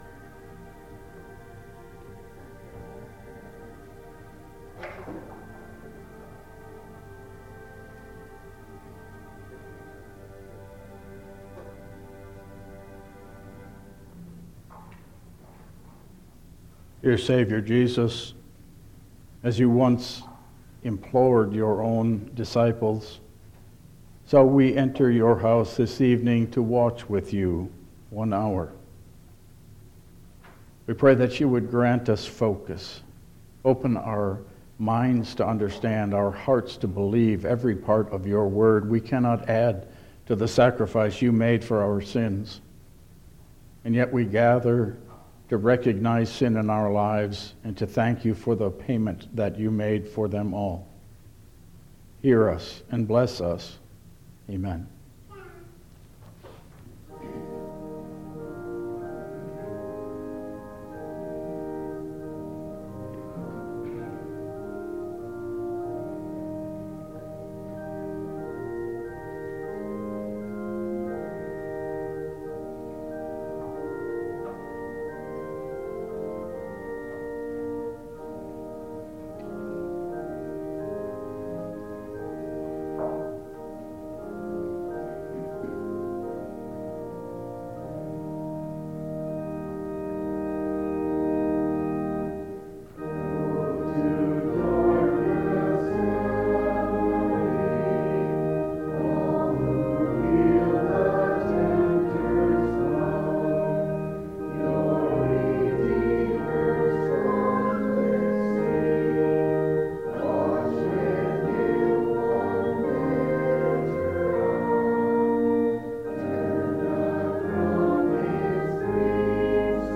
Download Files Printed Sermon and Bulletin
Service Type: Lenten Service